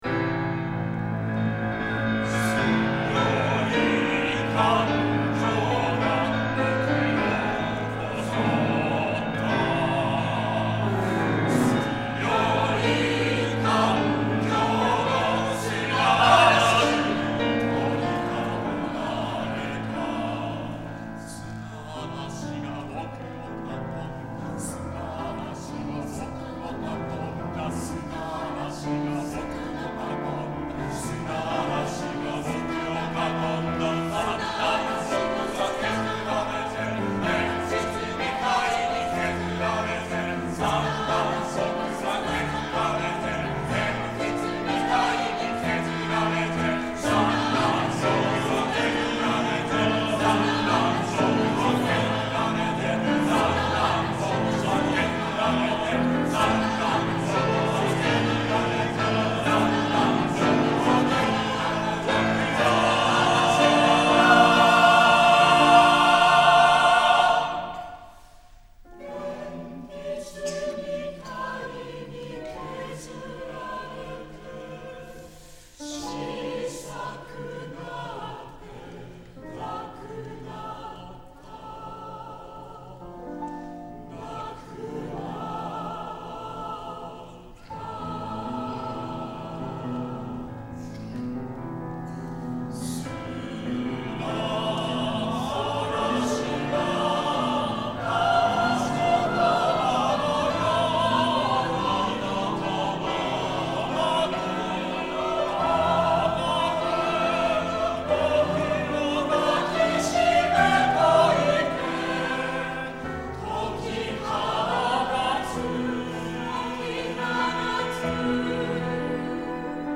V 強い感情が僕を襲った　上田真樹 混声合唱組曲「終わりのない歌」より 2:49 混声合唱団アプリス